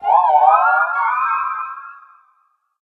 Cri de Sylveroy dans Pokémon HOME.